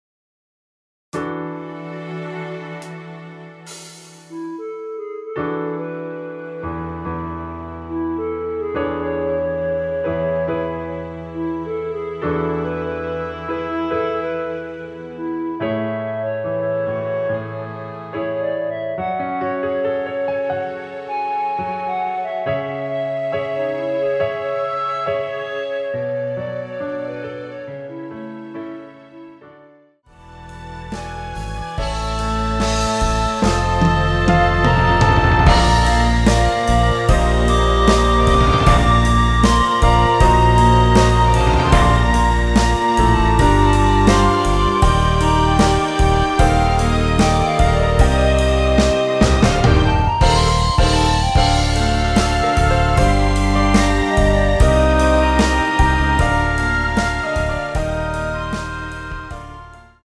원키에서(+6)올린 멜로디 포함된 MR입니다.
여자키 멜로디 포함된MR 입니다.(미리듣기 참조)
앞부분30초, 뒷부분30초씩 편집해서 올려 드리고 있습니다.
중간에 음이 끈어지고 다시 나오는 이유는
뮤지컬